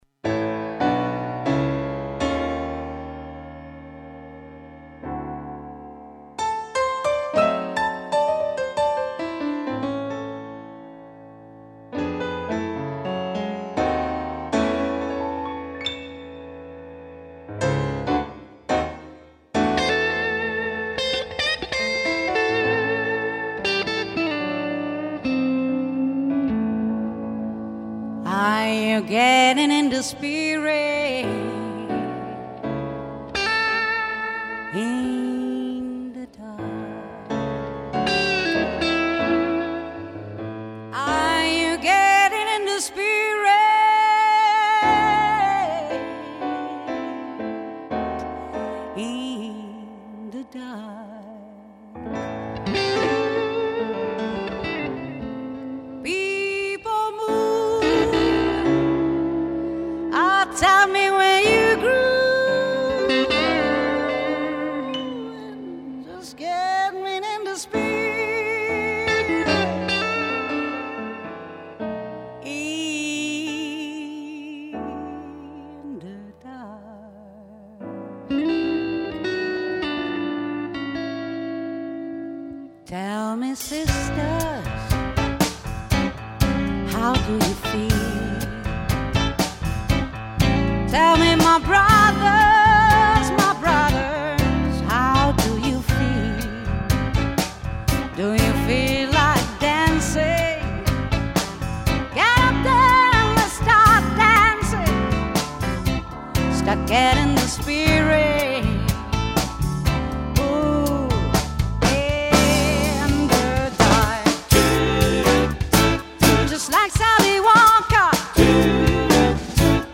GenereBlues / Soul